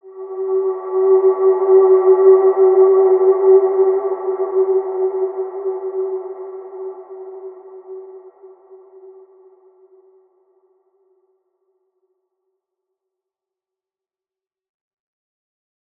Large-Space-G4-mf.wav